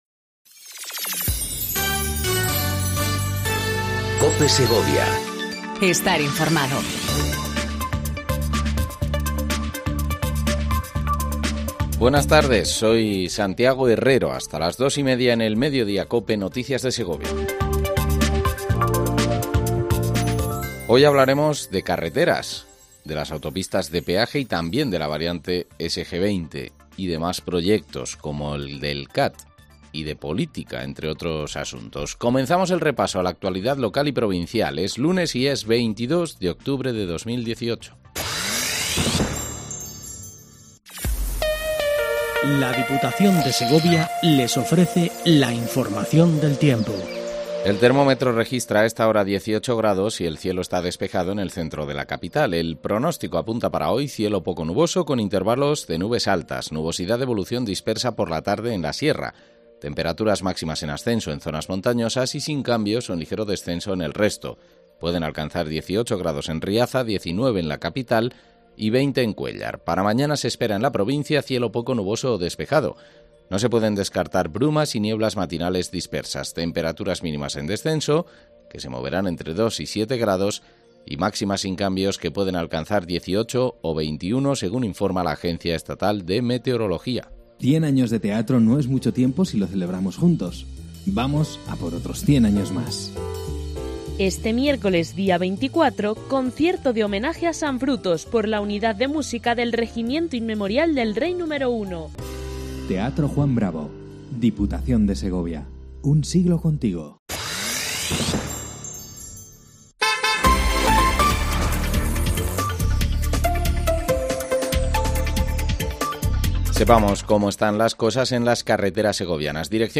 INFORMATIVO MEDIODÍA EN COPE SEGOVIA 14:20 DEL 22/10/18